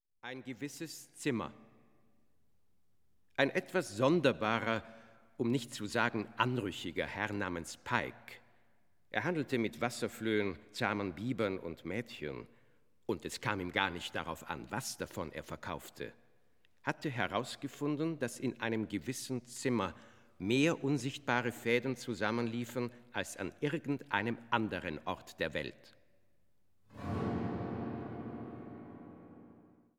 Klavier
Werke für Sprecher und Klavier.